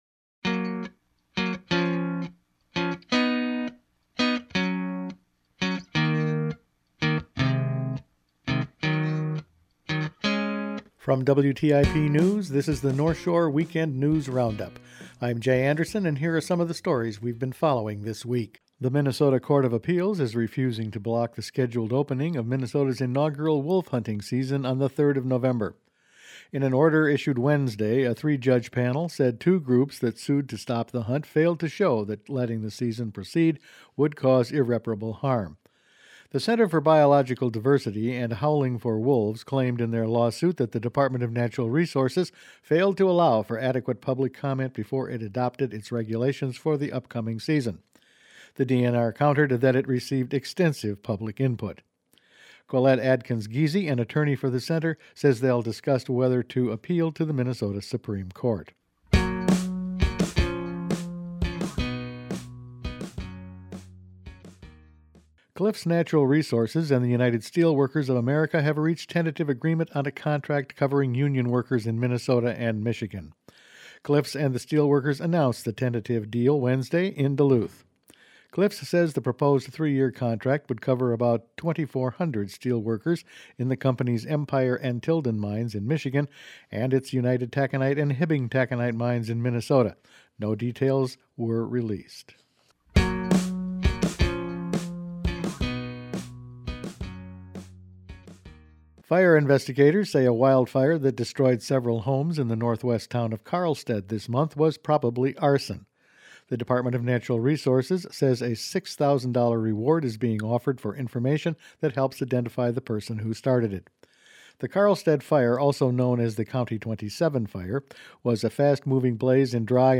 Weekend News Roundup for October 13